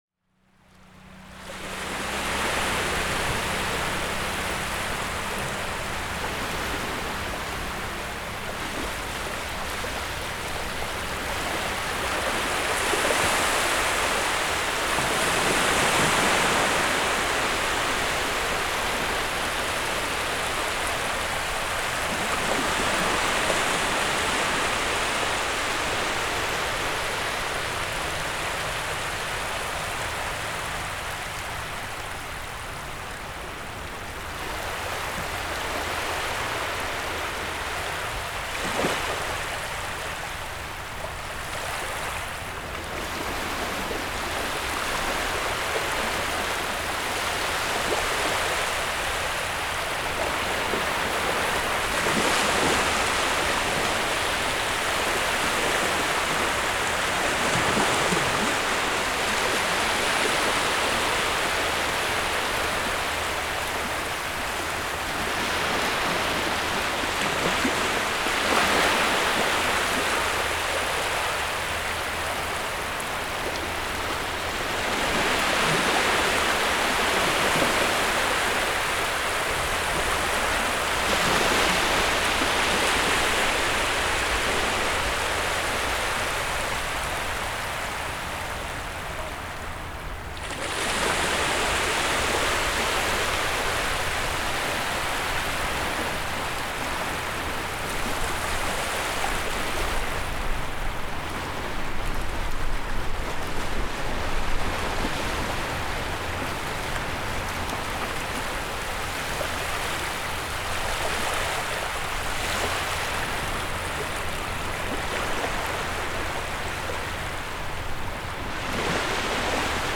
Marre d’être confiné…offrez-vous six minutes d’évasion, pur son garanti sans traitement.
Enregistrement Le Dossen-Juillet 2019